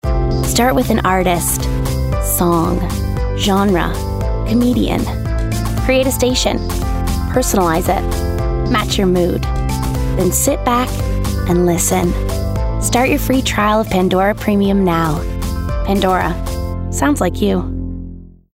20/30's, North American, Versatile/Confident/Assured